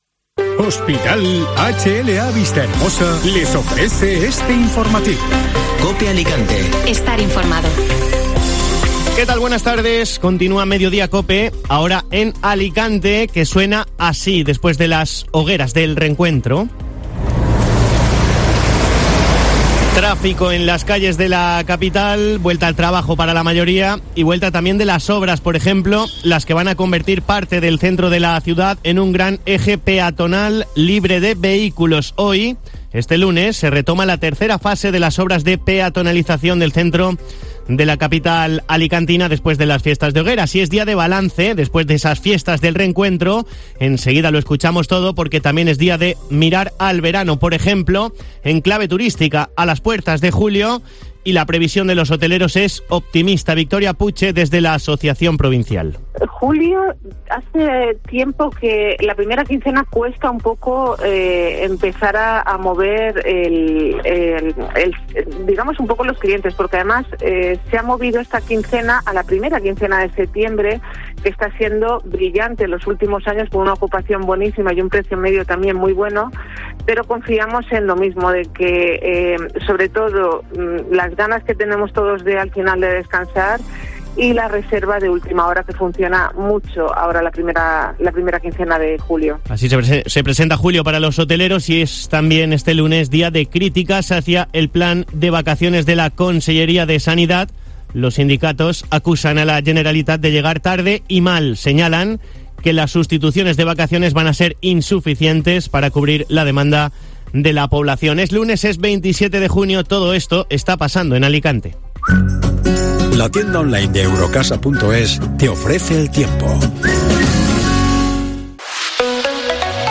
Informativo Mediodía COPE (Lunes 27 de junio)